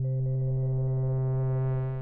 Pad - Loom.wav